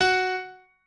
piano8_2.ogg